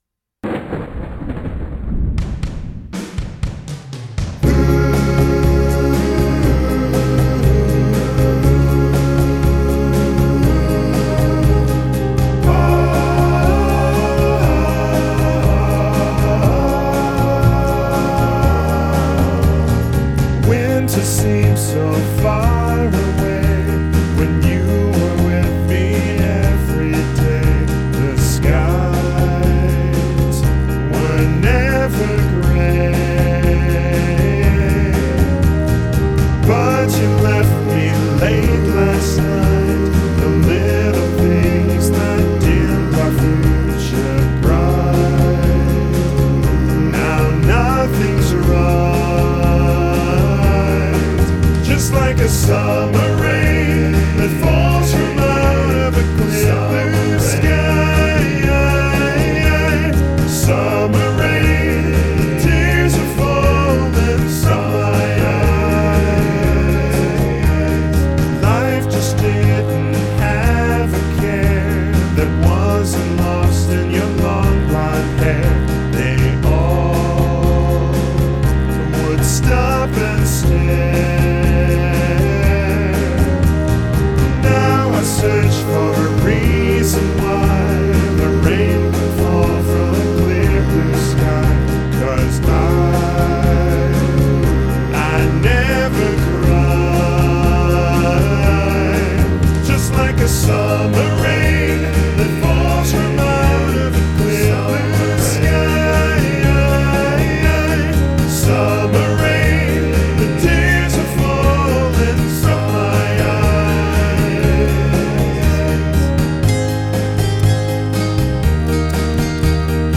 vocals / guitars / sequencing